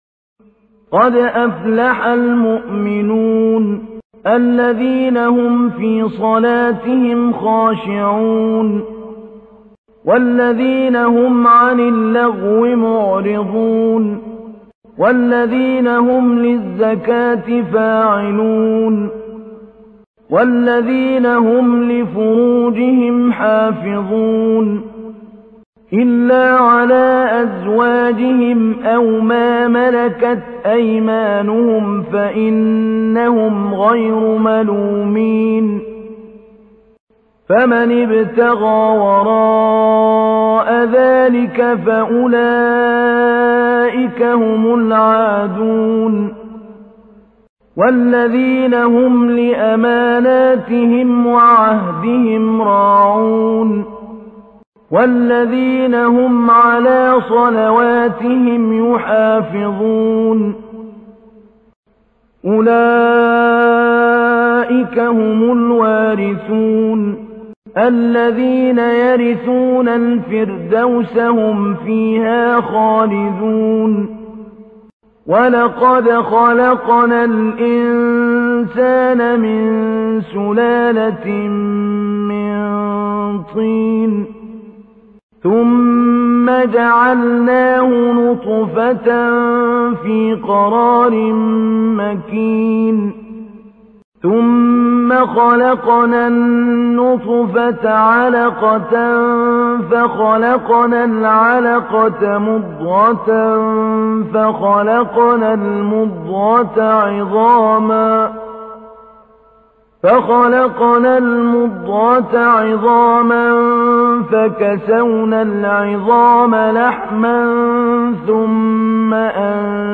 تحميل : 23. سورة المؤمنون / القارئ محمود علي البنا / القرآن الكريم / موقع يا حسين